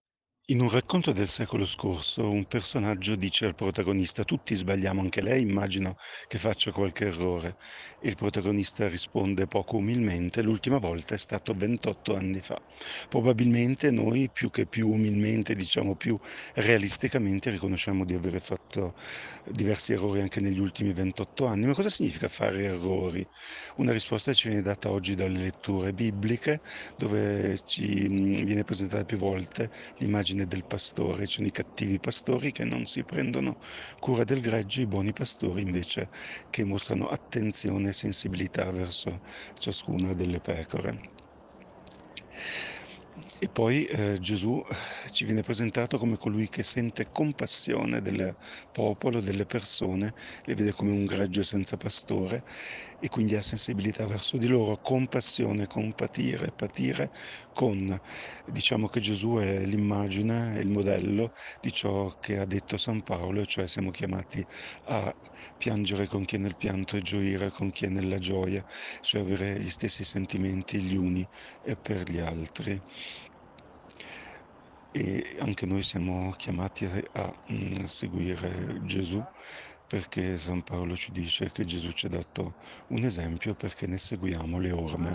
Meditazione Domenica 21 luglio 2024 – Parrocchia di San Giuseppe Rovereto